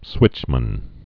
(swĭchmən)